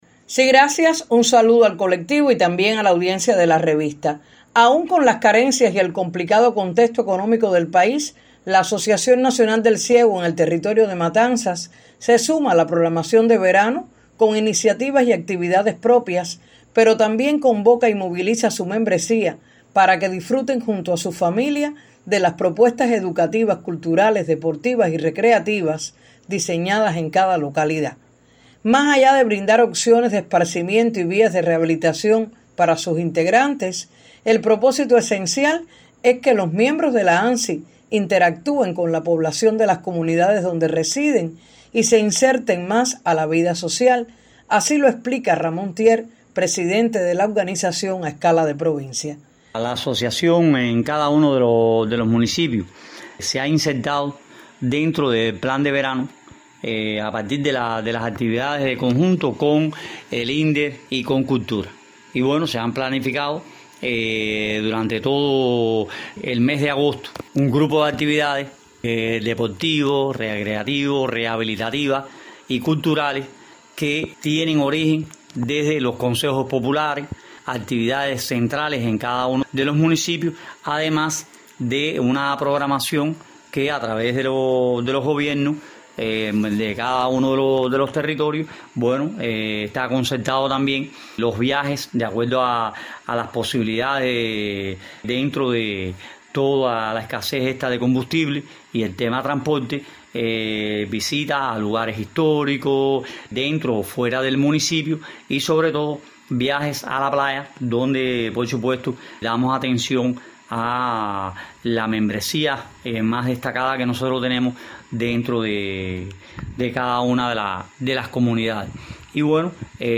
en la entrevista que concede a Radio 26